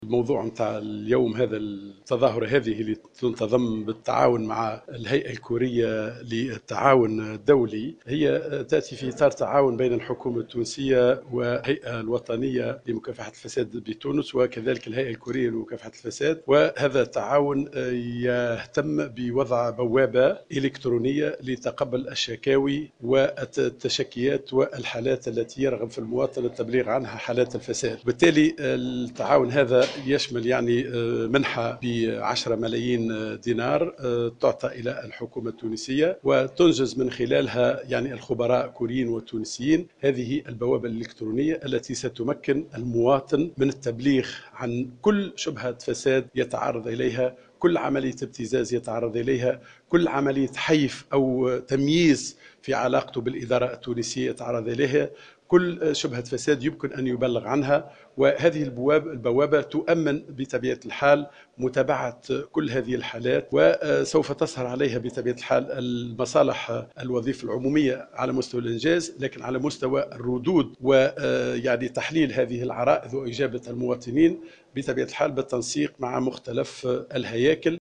أعلن كمال العيادي وزير الوظيفة العمومية والحوكمة ومكافحة الفساد في تصريح للجوهرة أف أم اليوم الأربعاء 24 فيفري 2016 أن الهيئة الكورية للتعاون الدولي ستقدم لتونس منحة بقيمة 10 ملايين دينار لانجاز بوابة إلكترونية للتبليغ عن حالات الفساد.